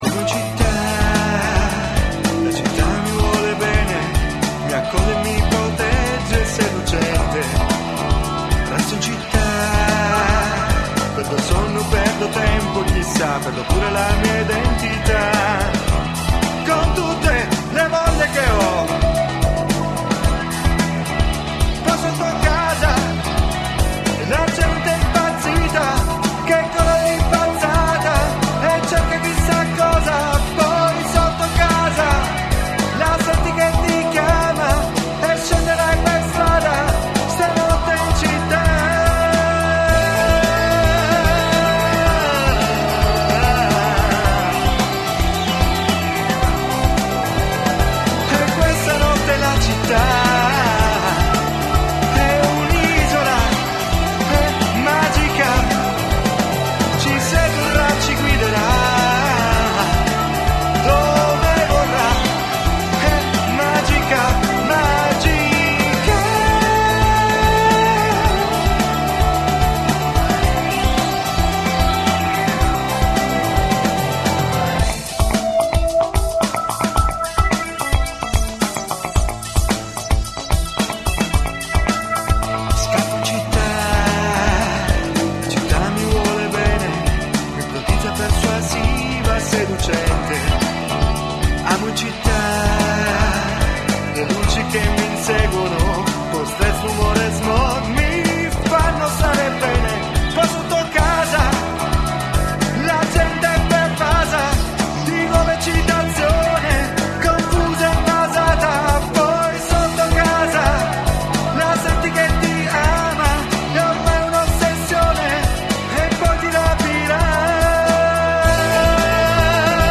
an unique mix of rock and techno influences